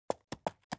pony_walk.wav